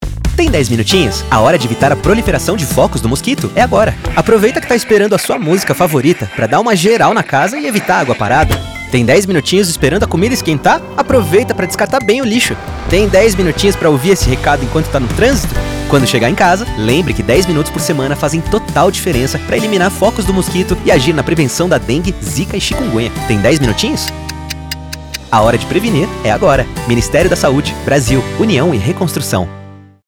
Áudio - Spot 30seg - Mosquito - 1,15mb